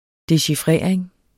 Udtale [ deɕiˈfʁεˀɐ̯eŋ ]